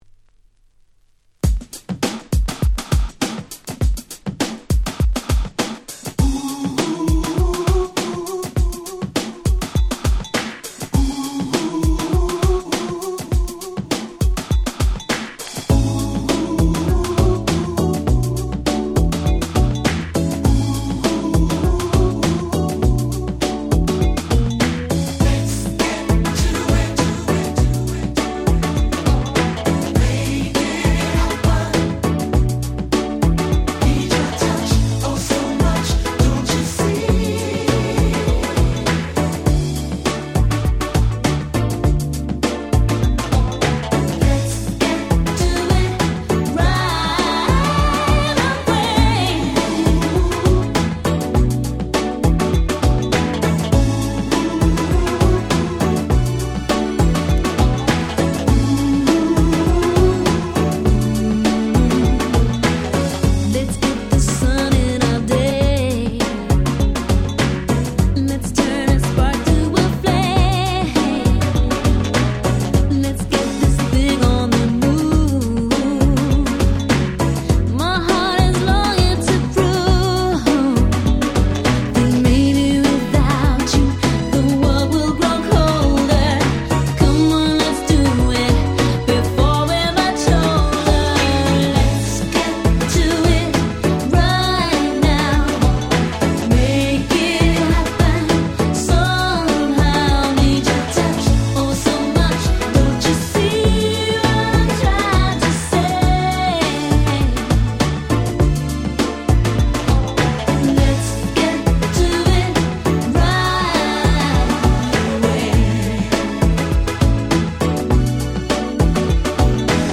コーナーストーン 90's キャッチー系 NJS ハネ系 New Jack Swing ニュージャックスウィング